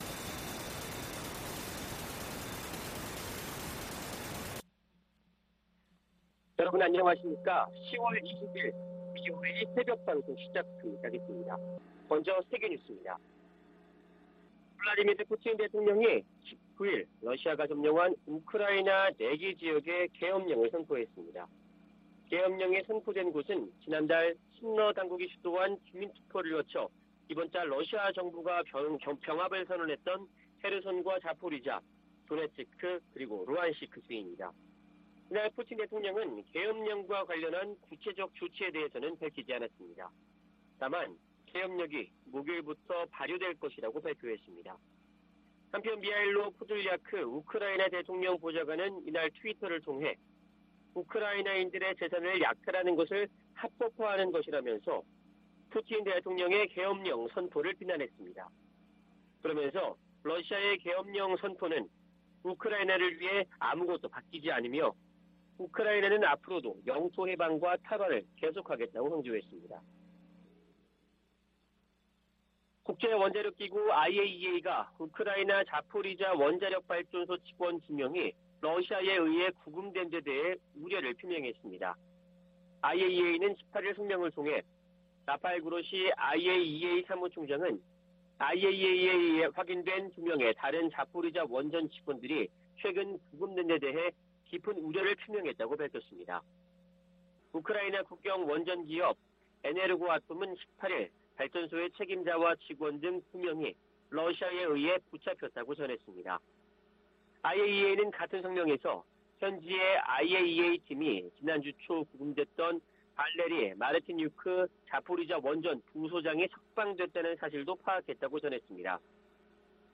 VOA 한국어 '출발 뉴스 쇼', 2022년 10월 20일 방송입니다. 북한이 18일 밤부터 19일 오후까지 동해와 서해 완충구역으로 350여 발의 포병 사격을 가하면서 또 다시 9.19 남북군사합의를 위반했습니다. 미 국무부는 북한의 포 사격에 대해 모든 도발적 행동을 중단할 것을 촉구했습니다. 미국 헤리티지재단은 '2023 미국 군사력 지수' 보고서에서 북한의 핵을 가장 큰 군사적 위협 중 하나로 꼽았습니다.